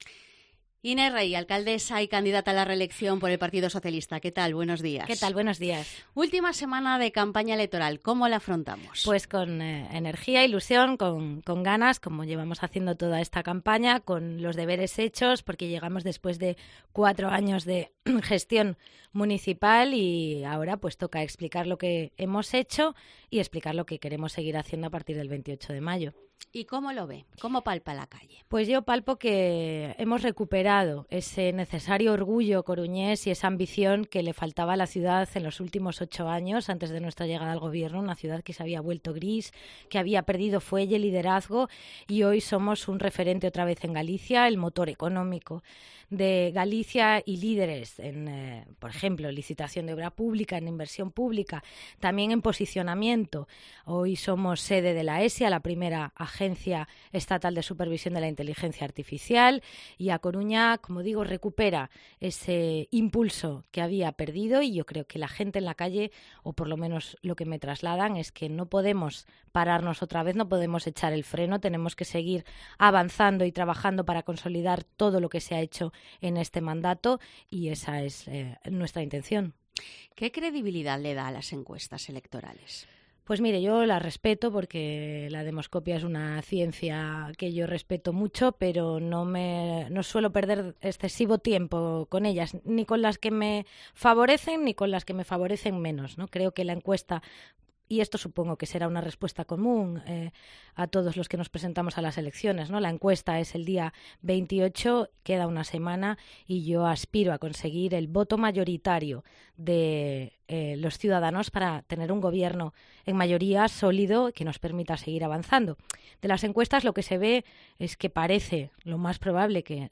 Entrevista a Inés Rey (PSOE), candidata a la alcaldía de A Coruña